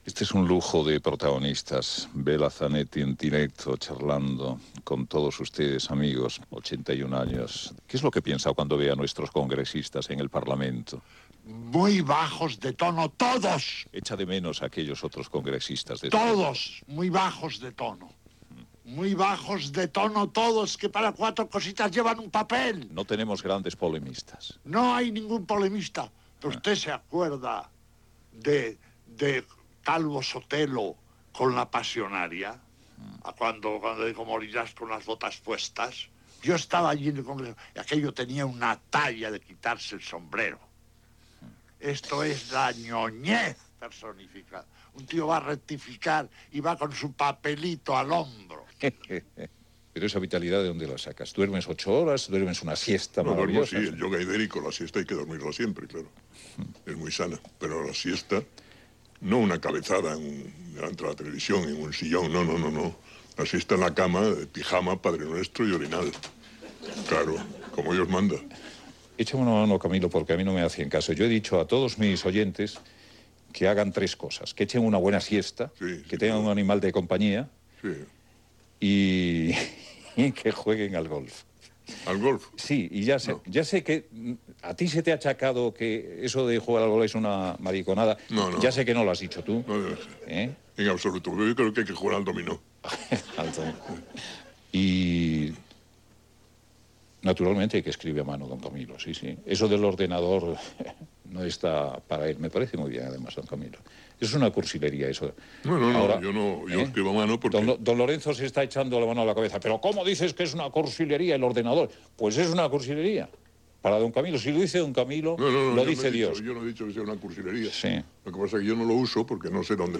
Resum d'entrevistes a José Vela Zanetti, Camilo José Cela, Felipe González i José María Aznar
Info-entreteniment